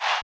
chant2.wav